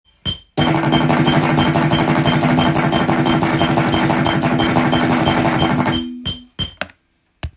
オルタネイトピッキングを使用します。
このように脱力で弾くとBPM１８０で１６分音符を正確に弾くことが出来ます。
180.picking.m4a